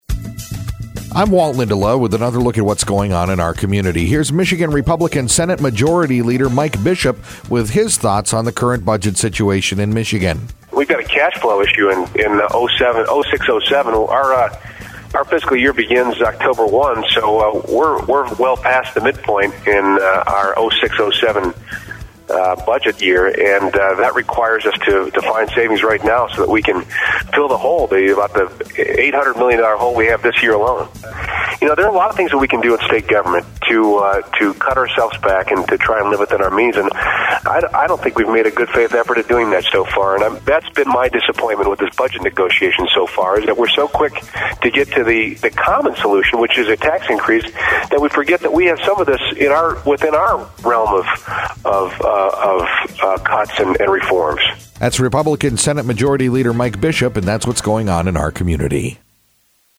INTERVIEW: Senator Mike Bishop, Michigan Senate Majority Leader